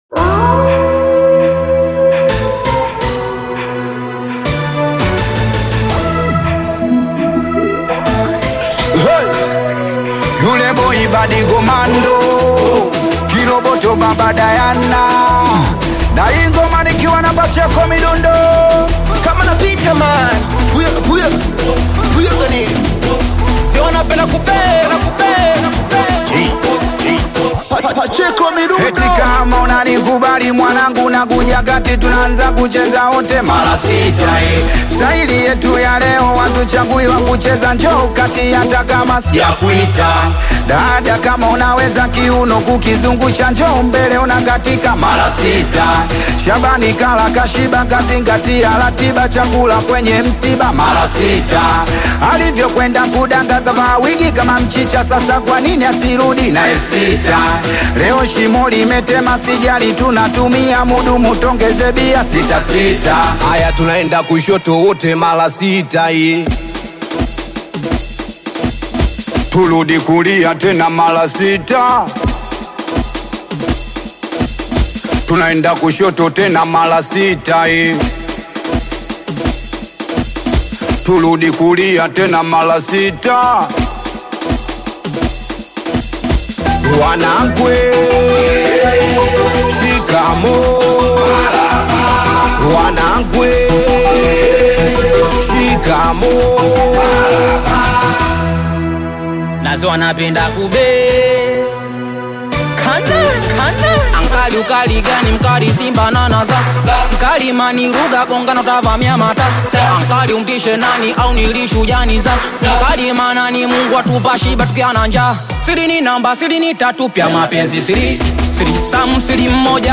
The highly energetic track